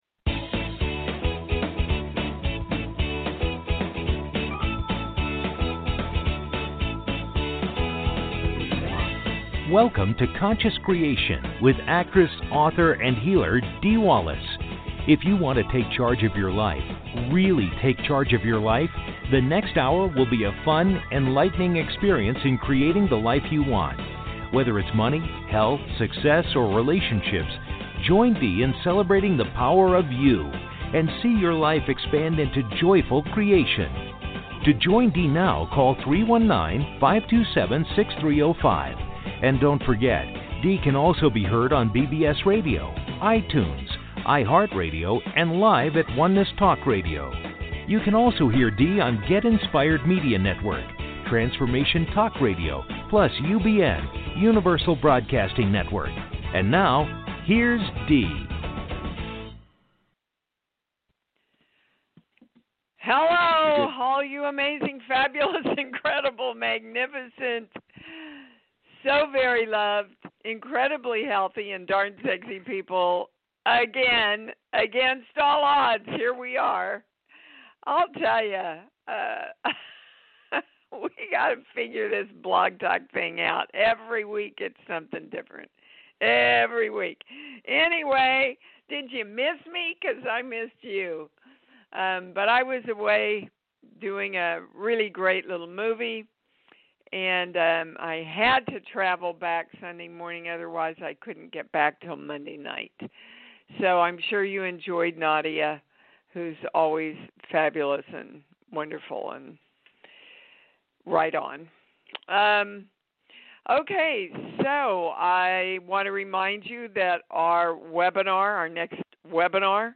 Talk Show Episode, Audio Podcast, Conscious Creation and with Dee Wallace on , show guests , about conscious creation,Dee Wallace,I am Dee Wallace, categorized as Kids & Family,Paranormal,Philosophy,Society and Culture,Spiritual,Access Consciousness,Medium & Channeling,Psychic & Intuitive,TV & Film